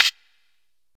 Hat (27).wav